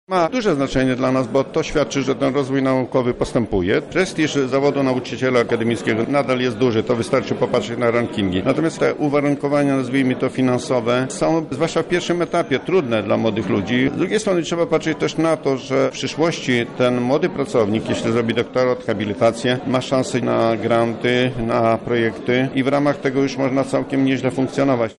W Auli Uniwersyteckiej Wydziału Prawa i Administracji UMCS odbyła się promocja 83 doktorów, w tym nadano 32 habilitacje.
O znaczeniu tego wydarzenia dla uczelni mówi jej rektor Stanisław Michałowski.